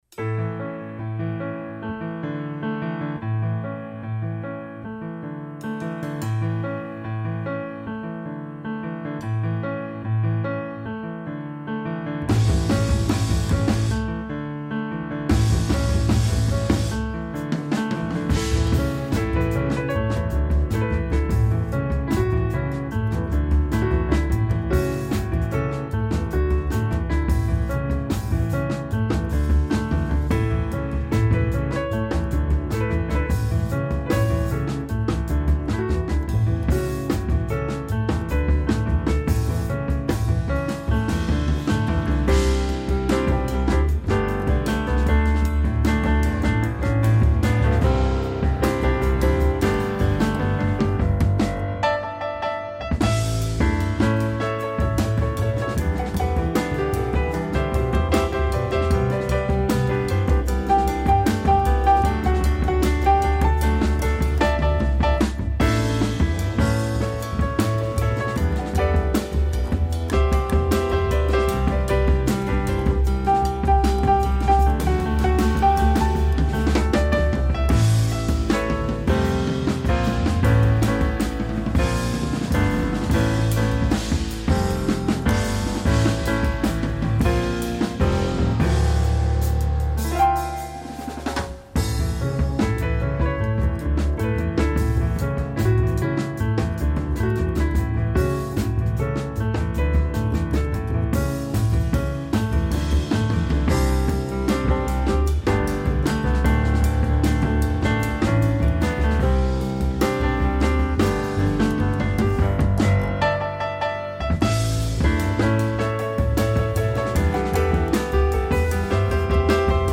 A poche ore dal concerto al Teatro del Gatto di Ascona del 14 aprile 2024, Rete Due intervista la musicista newyorkese